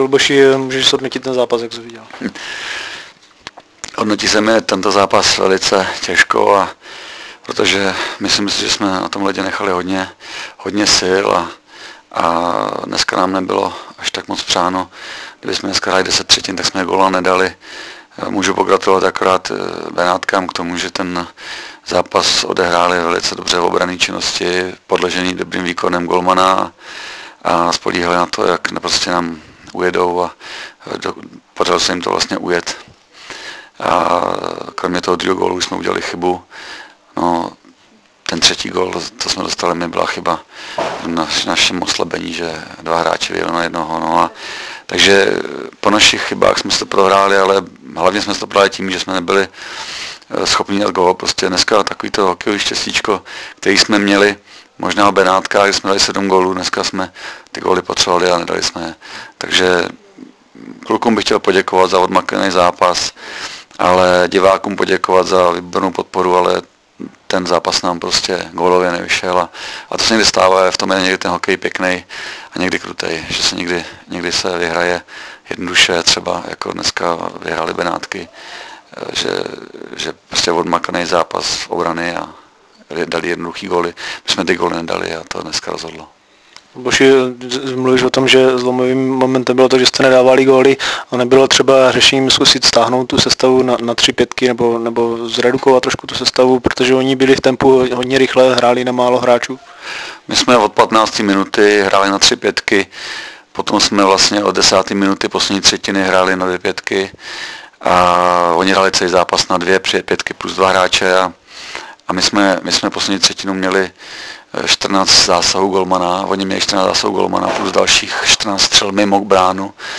po utkání dorostu TRE- BNJ 1:4